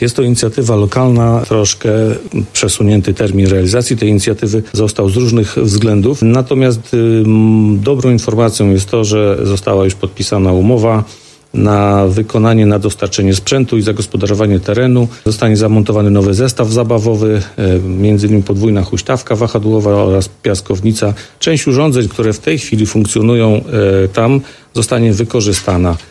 Mówił Mirosław Hołubowicz, zastępca prezydenta Ełku.